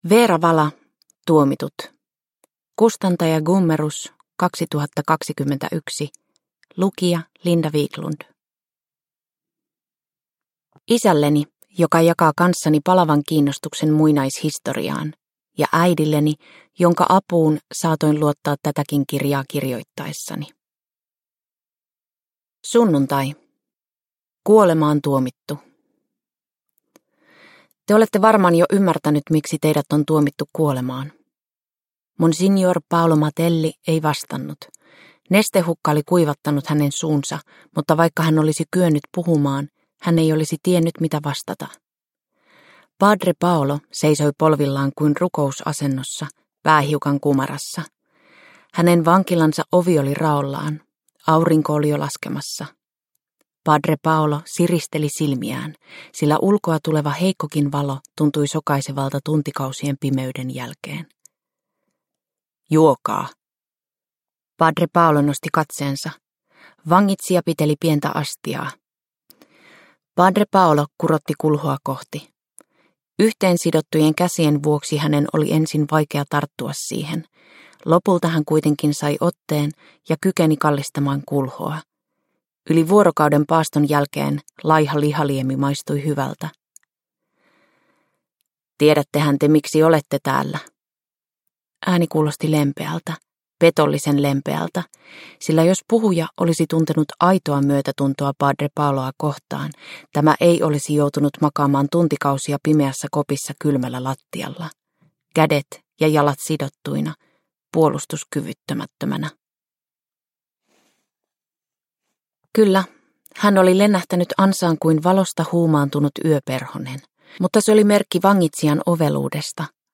Tuomitut (ljudbok) av Vera Vala